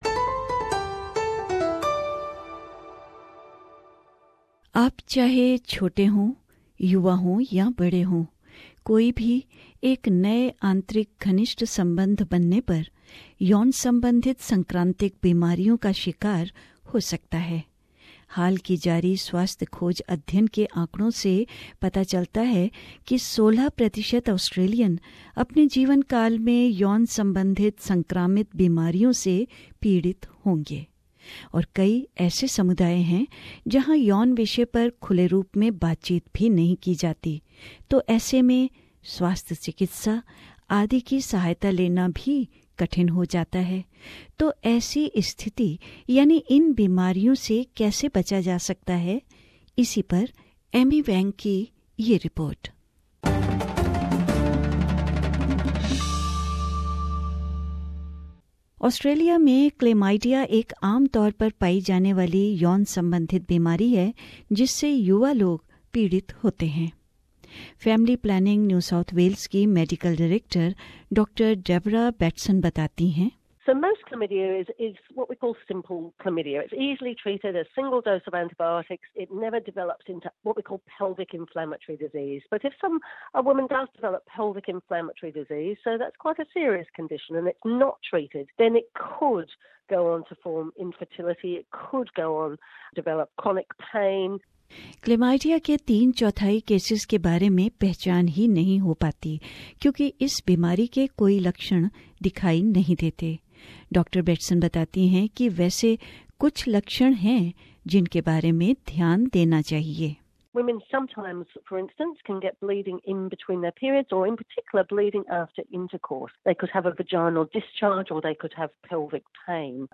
रिपोर्ट